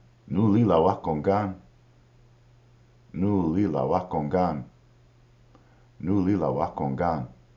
noo-li-la-wa-kô-gan